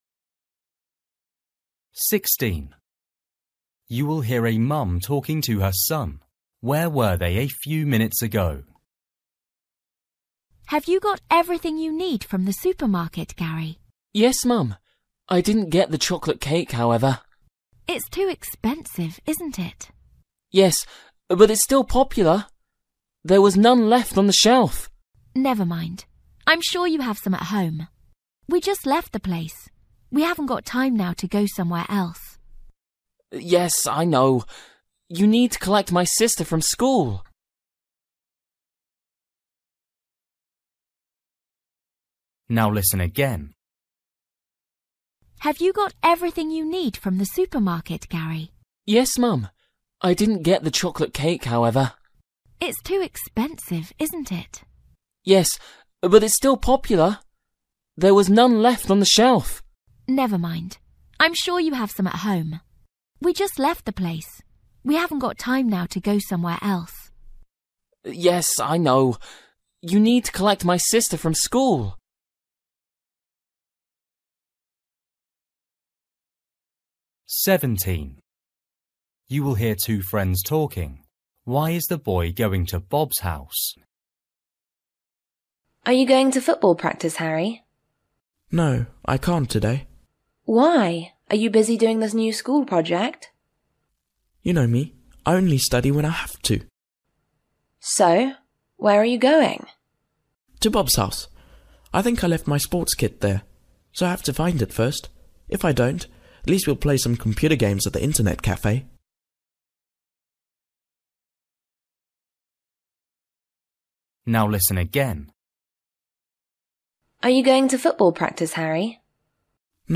Listening: everyday short conversations
16   You will hear a mum talking to her son. Where were they a few minutes ago?
17   You will hear two friends talking. Why is the boy going to Bob’s house?
18   You will hear a husband and wife talking. Why doesn’t the woman want the ring?
20   You will hear two neighbours talking. Why are they upset?